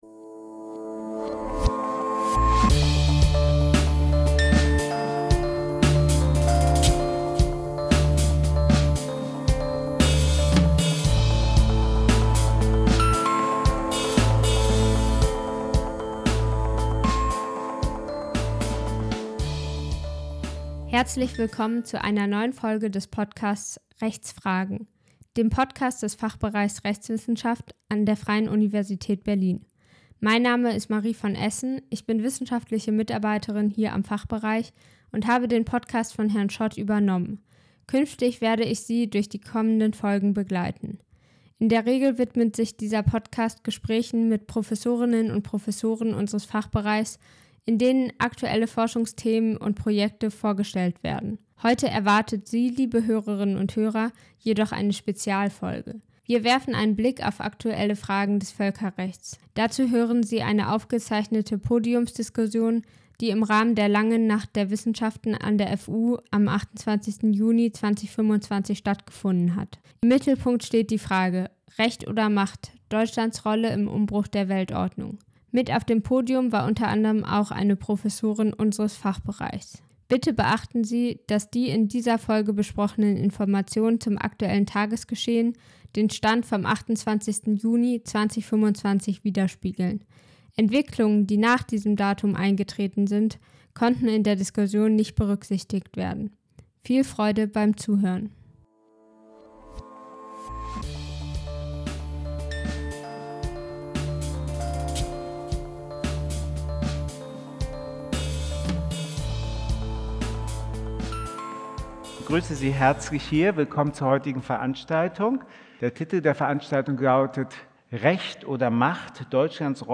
Die drei Panel-Teilnehmer*innen diskutieren die wachsende Entfremdung vom Völkerrecht und Risiken für Demokratie und Rechtsstaatlichkeit aus unterschiedlichen Perspektiven. Aufgenommen wurde diese Folge am 28. Juni 2025 im Rahmen der Langen Nacht der Wissenschaften.